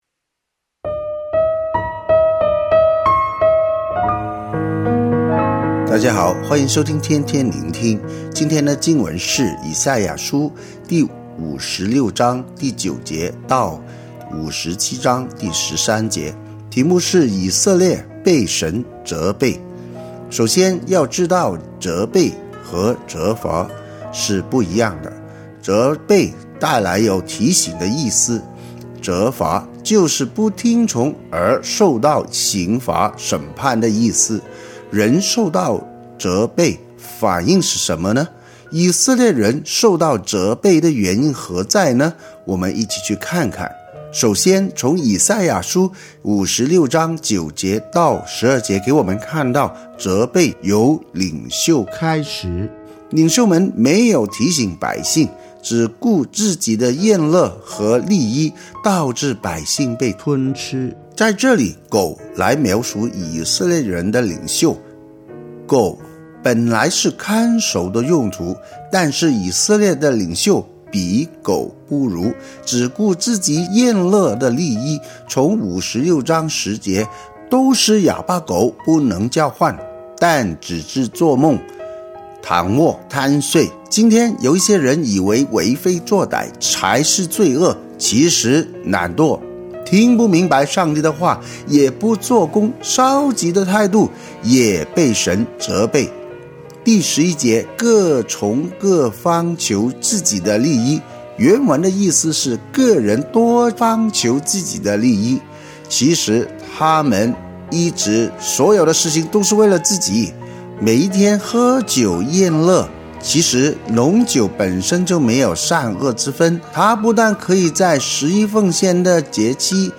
普通話錄音連結🔈